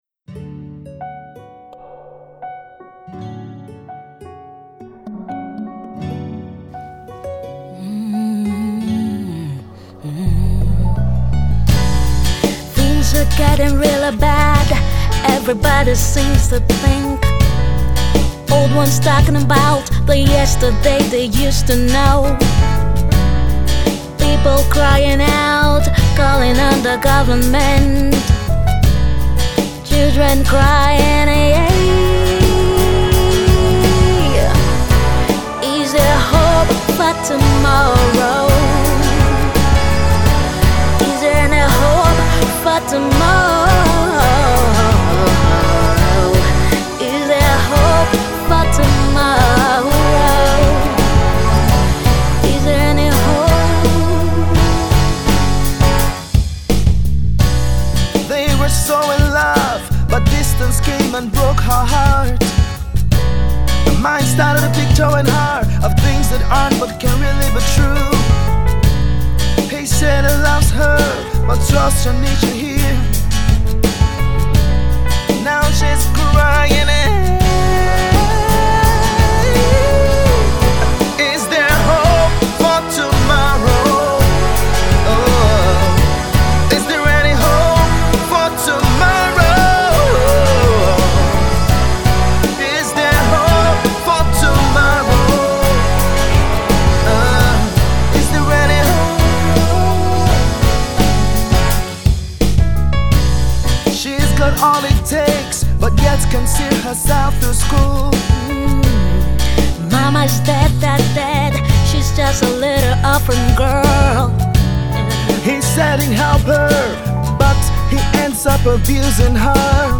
guitar laced sound